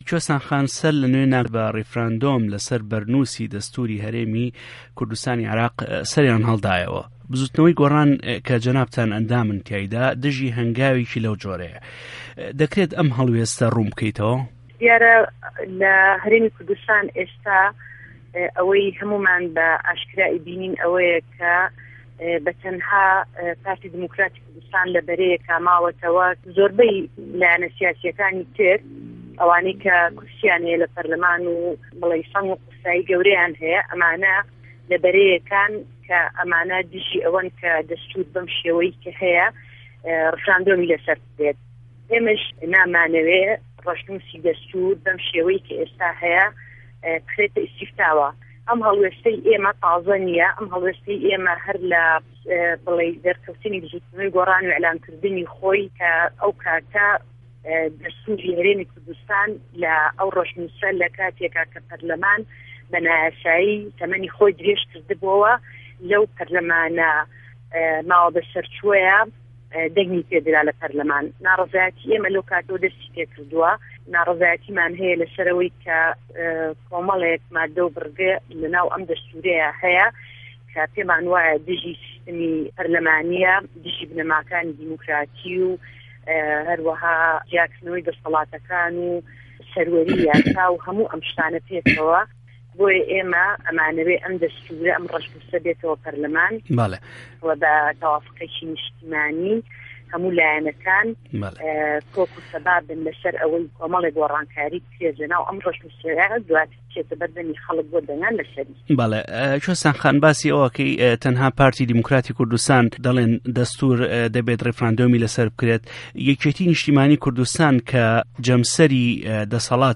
وتووێژی کوێستان محه‌مه‌د